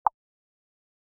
دانلود صدای اعلان خطر 52 از ساعد نیوز با لینک مستقیم و کیفیت بالا
جلوه های صوتی